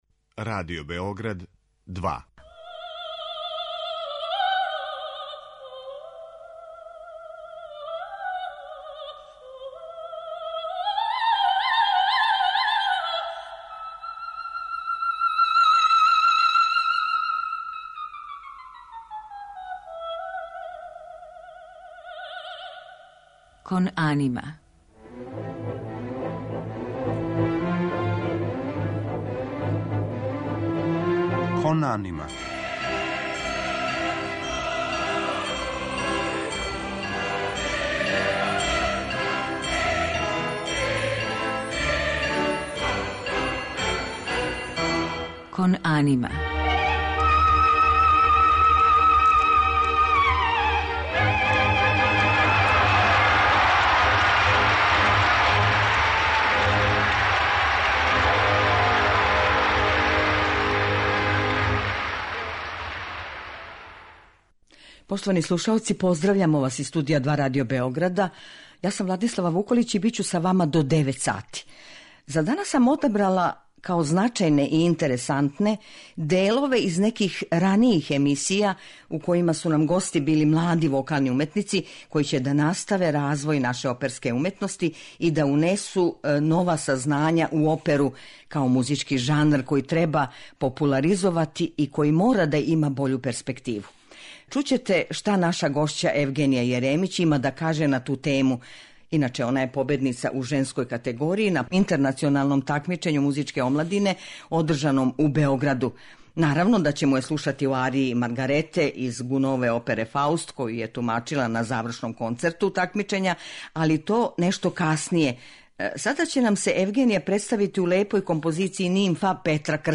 Емисија оперске музике